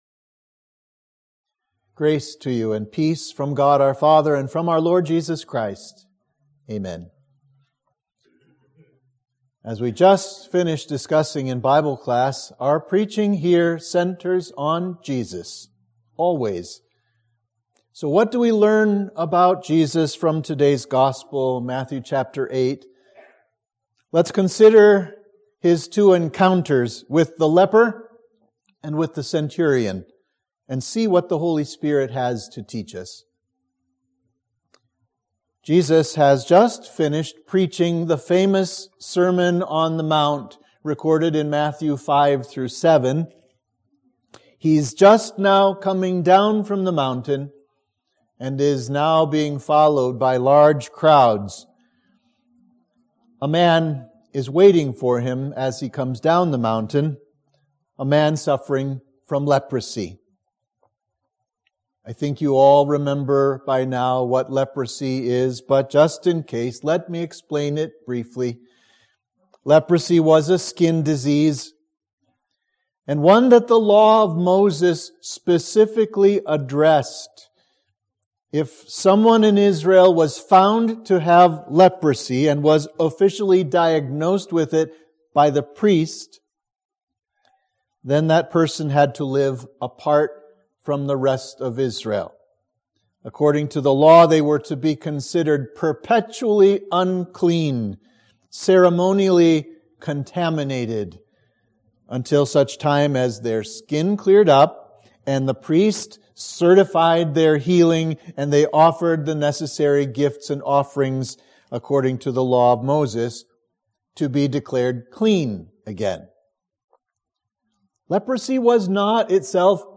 Sermon for Epiphany 3